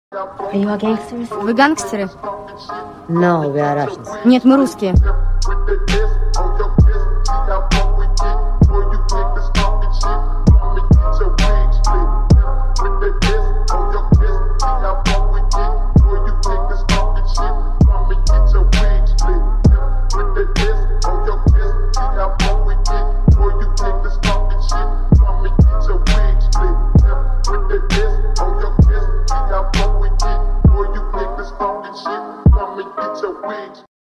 Громкие Рингтоны С Басами
Фонк Рингтоны
Рэп Хип-Хоп Рингтоны